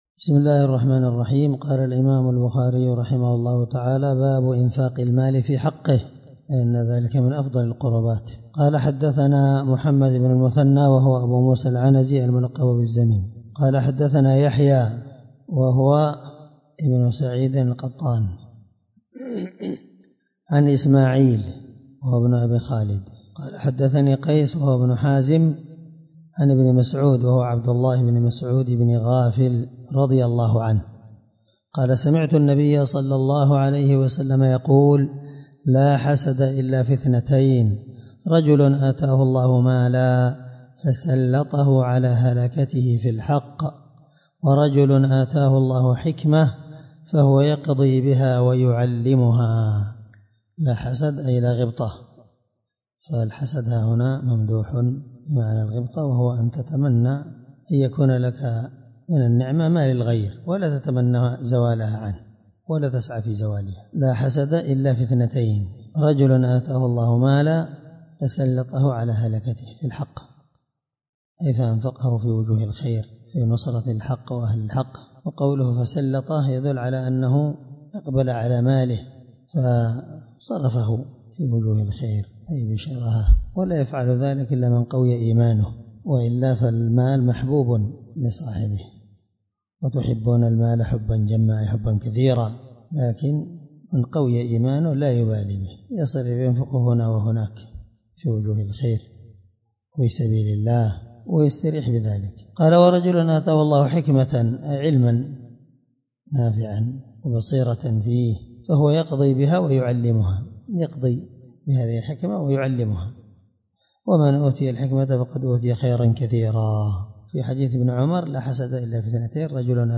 الدرس 8من شرح كتاب الزكاة حديث رقم(1409 )من صحيح البخاري